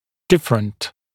[‘dɪfrənt][‘дифрэнт]разный, различный, отличающийся